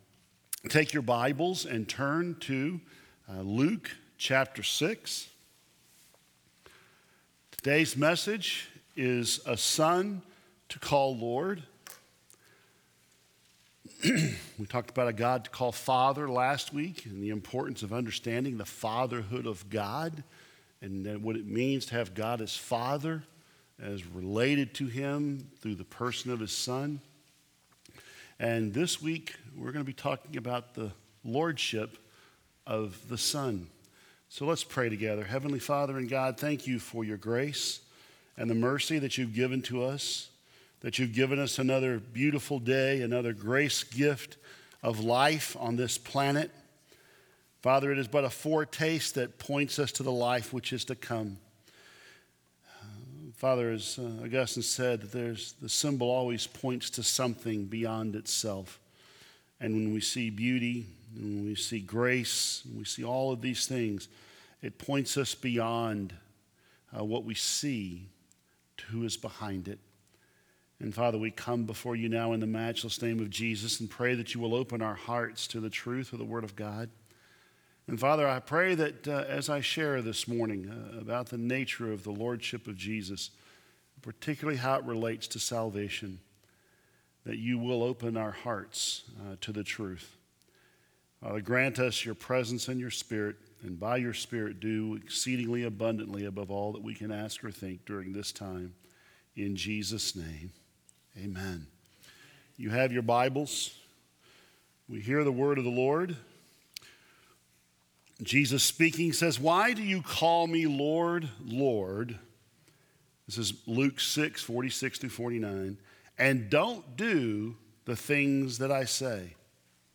2016 Related Share this sermon